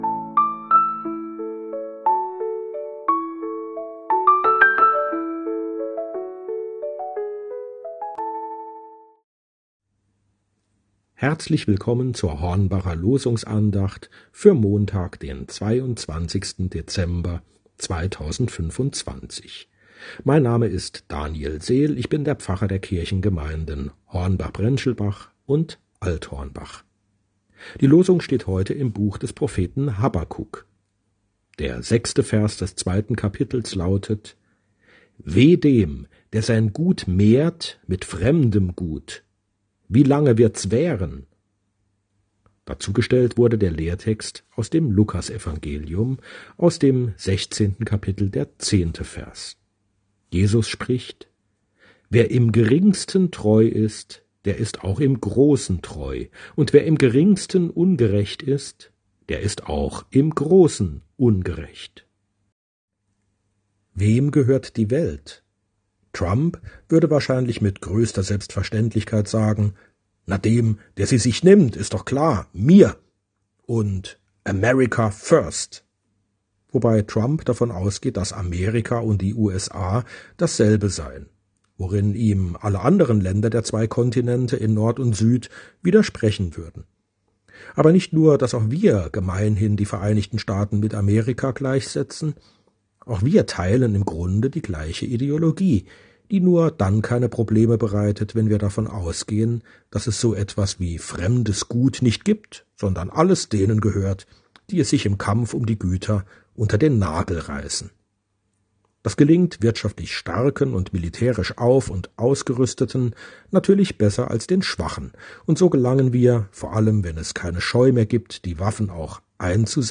Losungsandacht für Montag, 22.12.2025 – Prot.